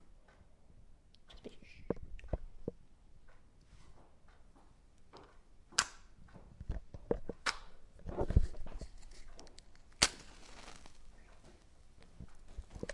现场录音" 匹配
描述：在火柴盒上击打火柴。